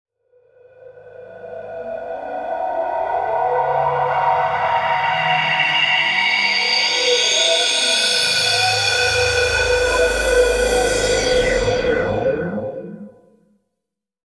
SPOOKYWIND.wav